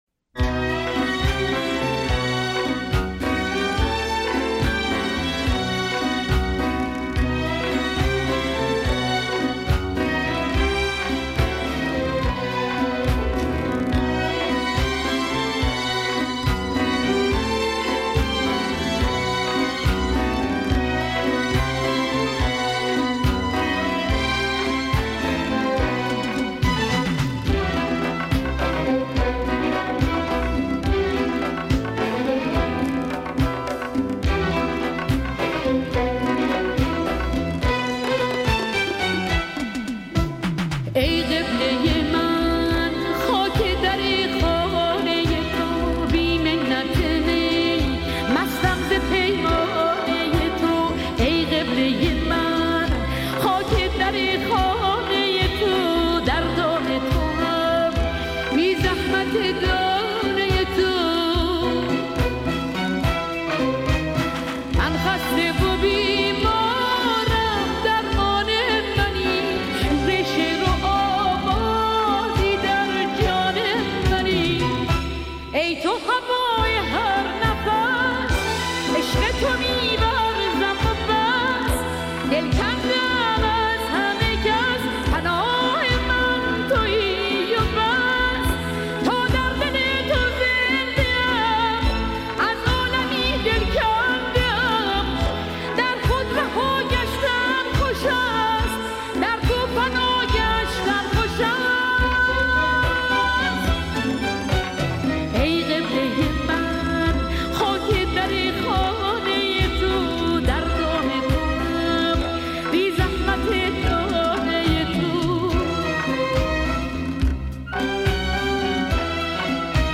دسته : پاپ